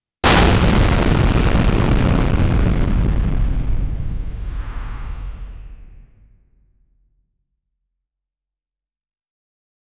a-sudden-cinematic-boom-w-rfdbdofr.wav